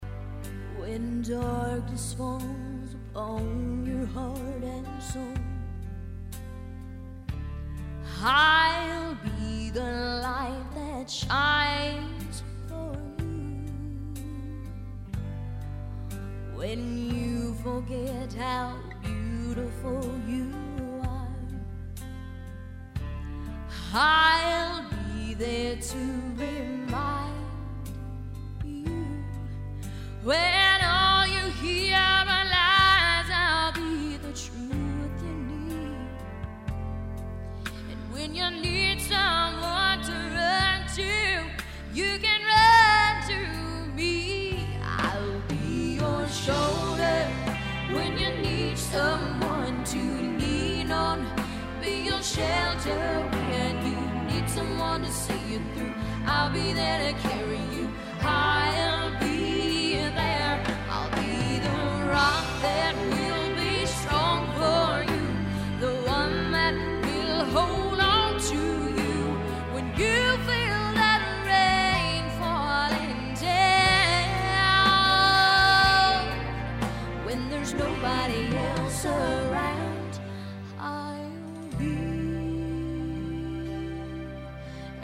C  O  U  N  T  R  Y     C  O  V  E  R  S